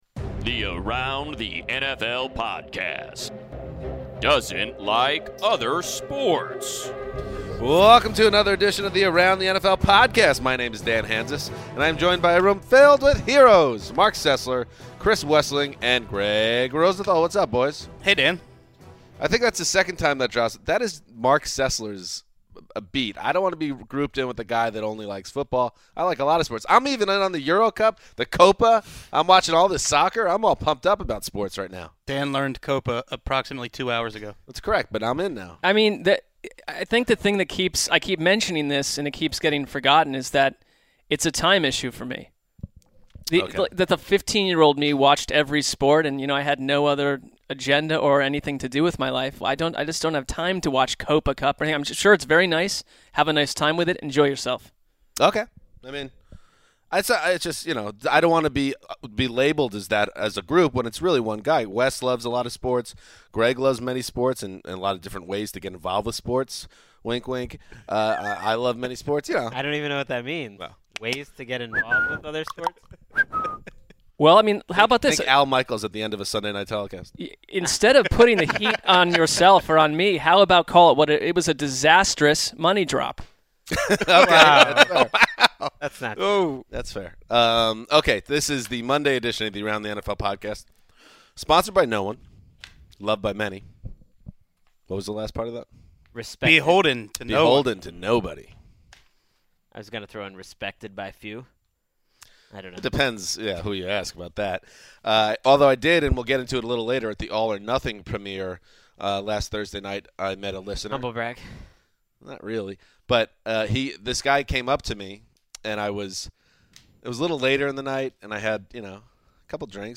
The guys then debate which young quarterbacks have the brightest future in the NFL.